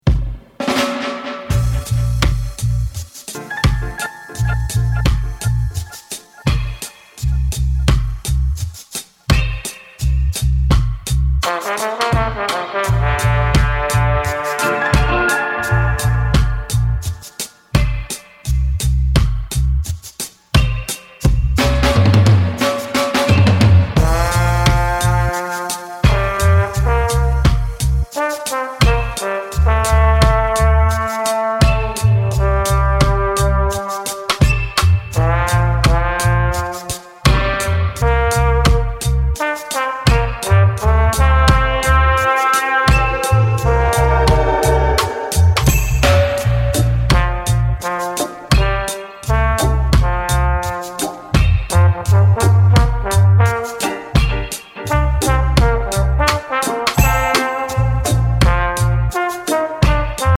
トロピカルでハートフルな音源をゆるく紡いでいった極上の一枚、これはタイムレスに楽しめること間違いなしですね！